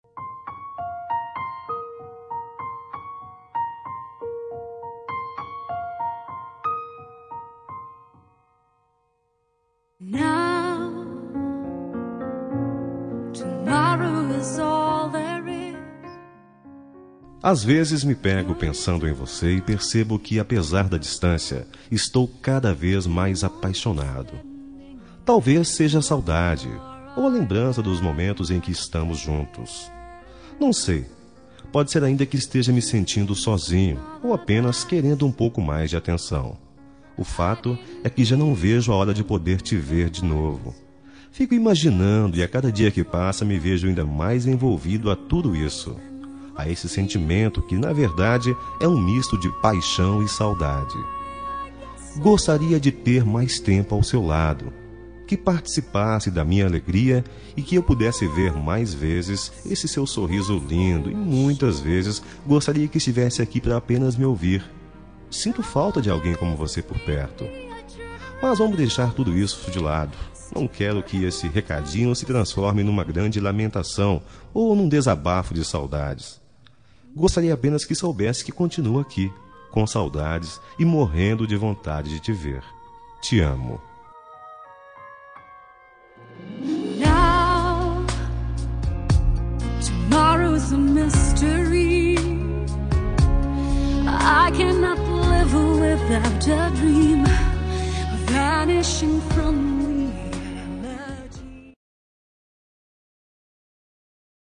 Telemensagem Romântica Distante – Voz Masculina – Cód: 760